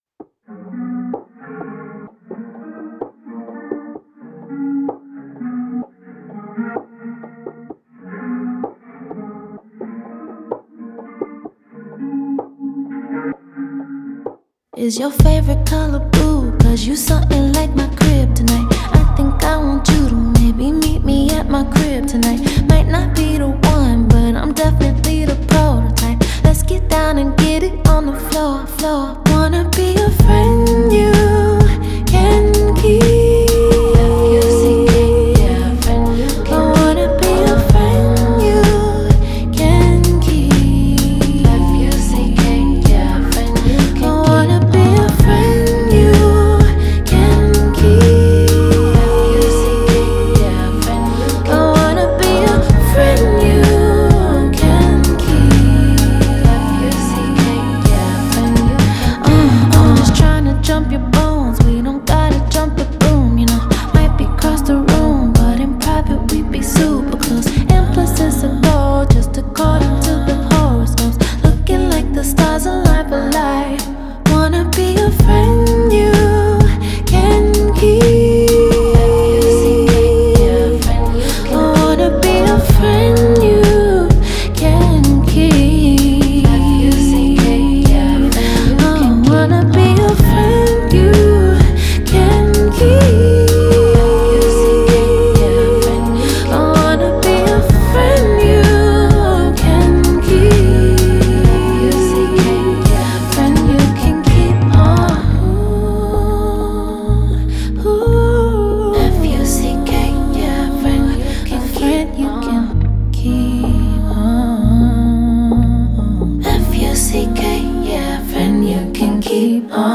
энергичная R&B песня